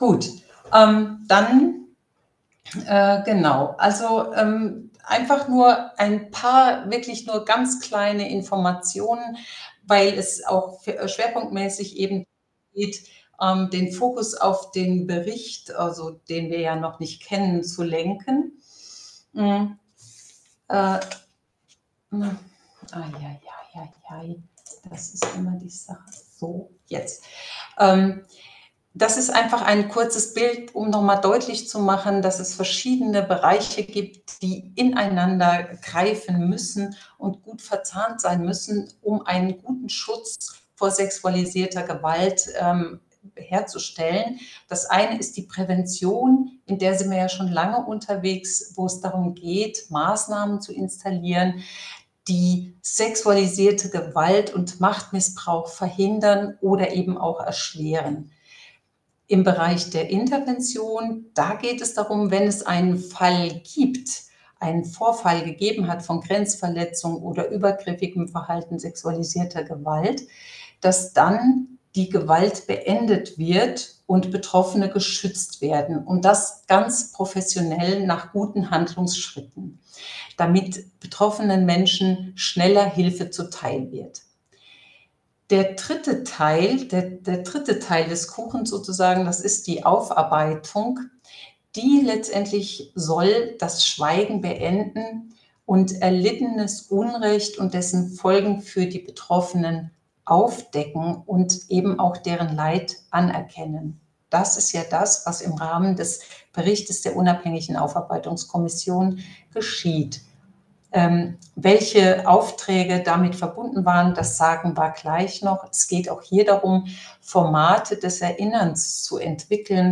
Digitale Veranstaltung mit Bischof Dr. Michael Gerber